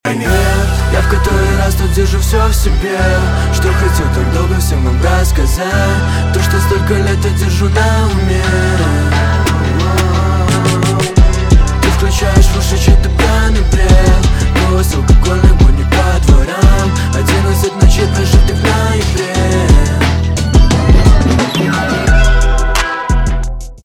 альтернатива
грустные
пианино , басы